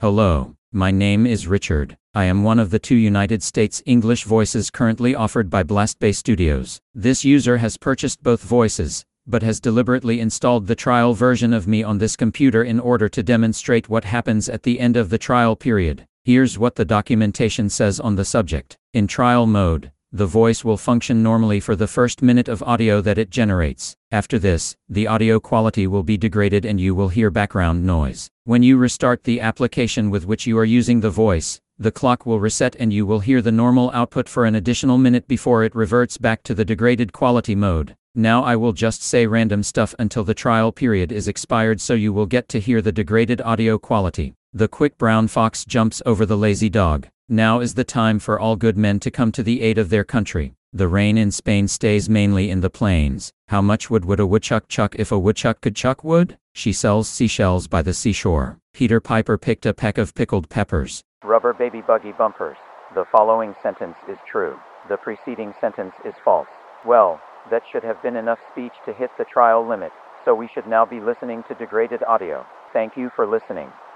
Here's a demonstration of Richard, one of the two new Blastbay formant-based neural text to speech voices. In this demo, I've deliberately installed the trial version on one of my computers so you get to hear the degraded audio once the one-minute trial period ends.